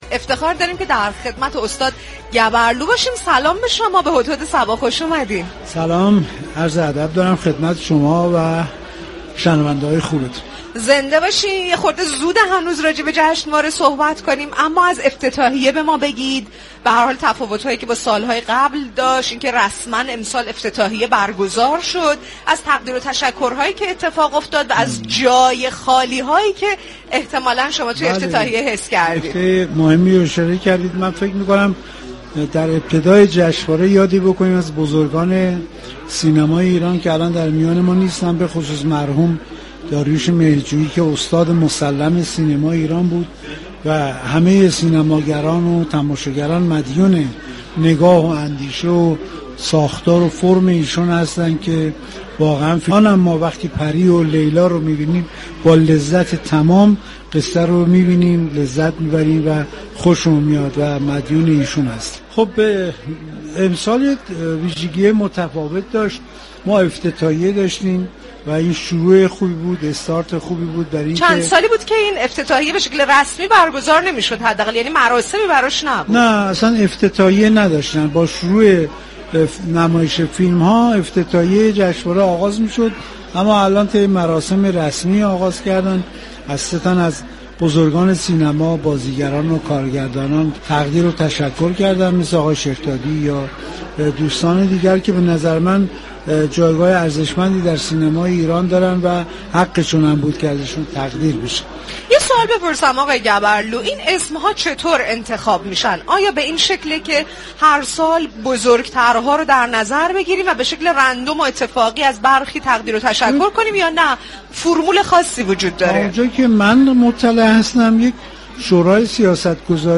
به گزارش روابط عمومی رادیو صبا، این شبكه رادیویی همزمان با برگزاری چهل‌ودومین جشنواره بین‌المللی فیلم فجر در برنامه فرهنگی «هدهد صبا» از محل برگزاری این جشنواره واقع در برج میلادتهران راهی آنتن می شود و مخاطبان را در جریان آخرین اخبار و رویداد های این جشنواره قرار می دهد.
این برنامه با گفتگو با مسولان، هنرمندان، خبرنگاران و ارائه گزارشی از رویداد های این جشنواره و نقد وبررسی اتفاقات جشنواره فیلم فجر از محل برگزاری این جشنواره همراه مخاطبان می شود.